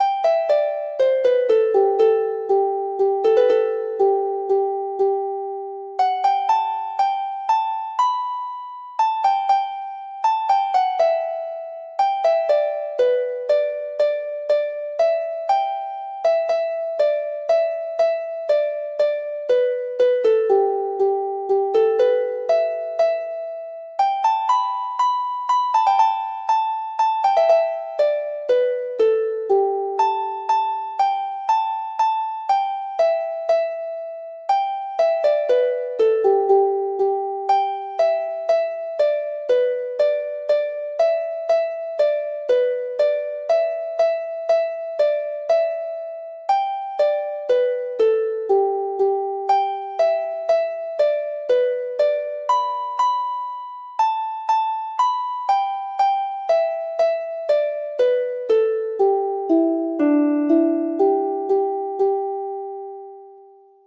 Harp  (View more Easy Harp Music)
Traditional (View more Traditional Harp Music)